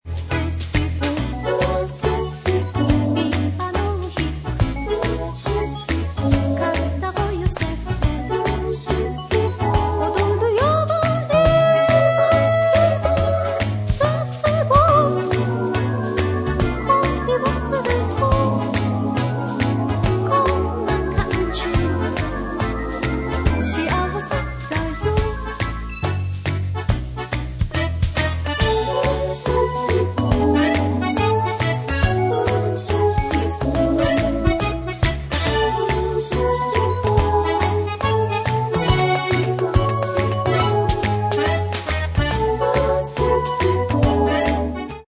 20年代-50年代のシャンソンの名曲を集めた珠玉の作品集。